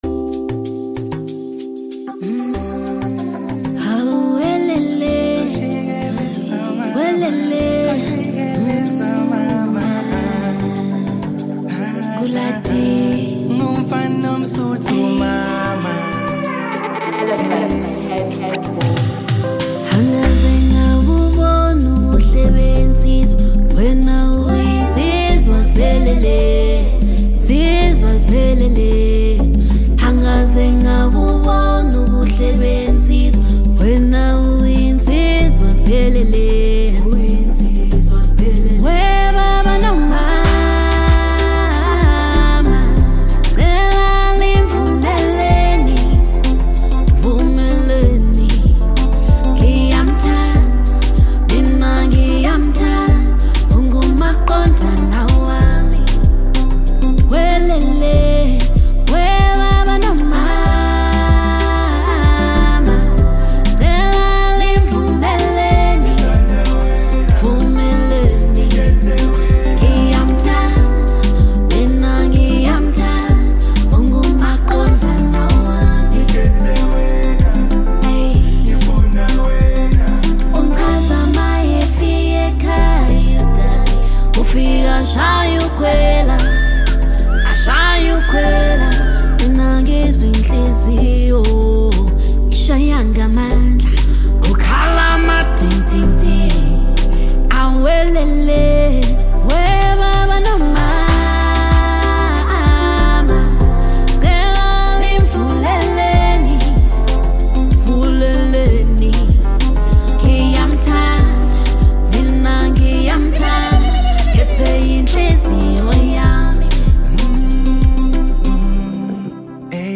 Home » Maskandi
Prominent South African Maskandi singer